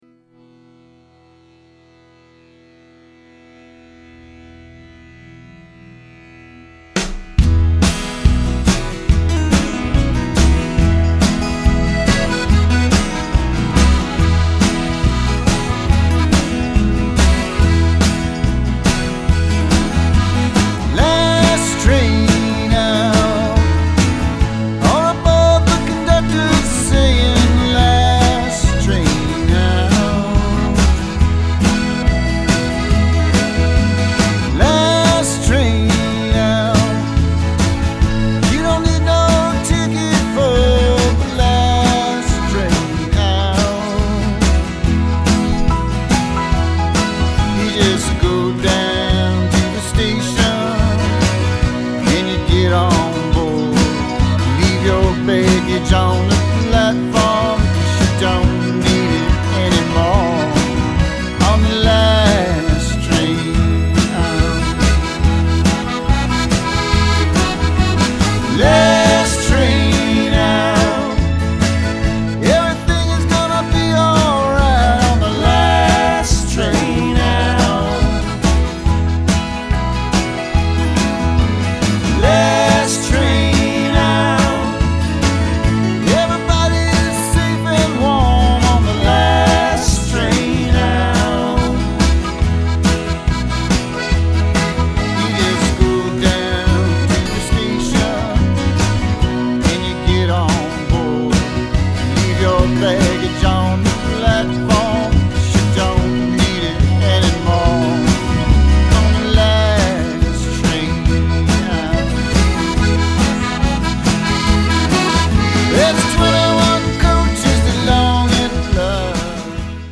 (Americana/Country-Rock/Blues)